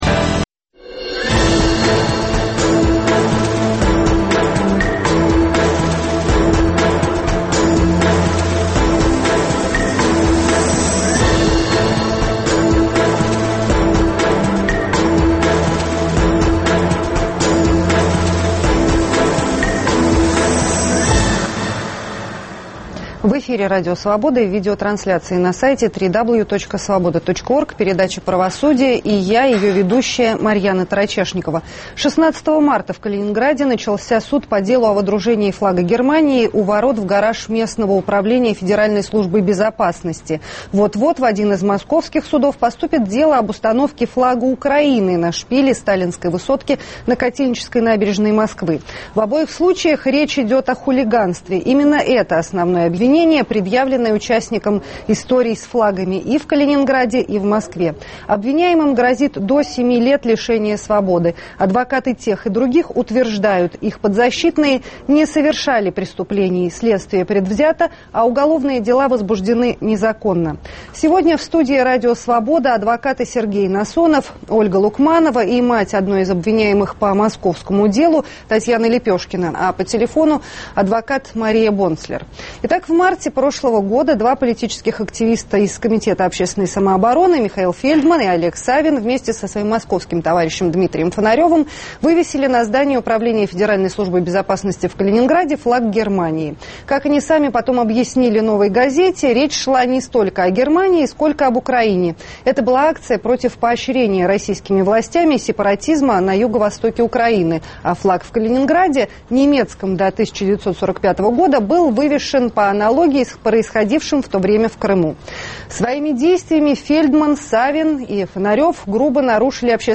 Адвокаты и тех, и других утверждают: их подзащитные не совершали преступлений, следствие предвзято, а уголовные дела возбуждены незаконно. В студии Радио Свобода